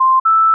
completion chime) respecting soundEffects setting
Audio assets: 3 generated WAV files for timer events.